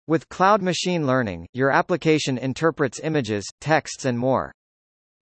All are professional business voices.
Male A
en-US-Wavenet-A.mp3